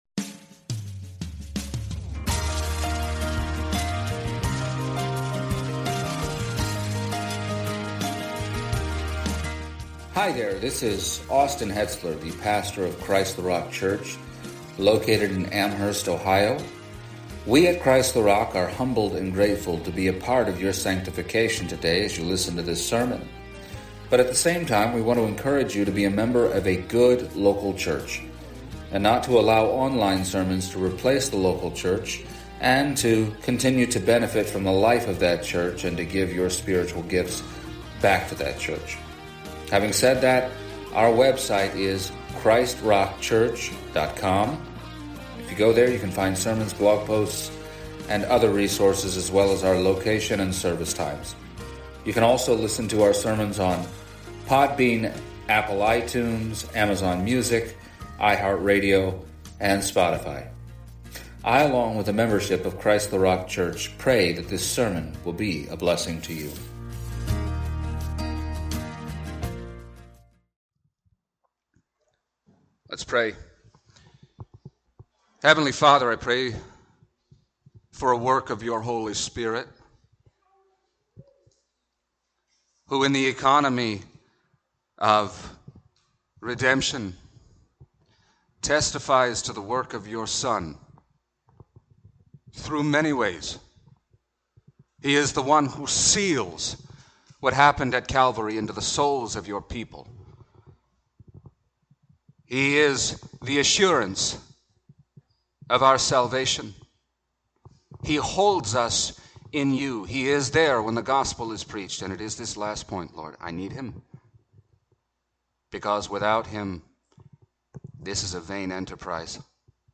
Exposition of the Epistle to the Hebrews Service Type: Sunday Morning Introductory survey of the epistle to the Hebrews.